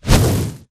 fireball4.ogg